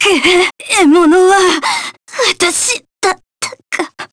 Yanne_L-Vox_Dead_jp.wav